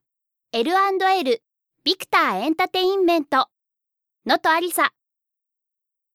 ボイスサンプル
ボイスサンプル１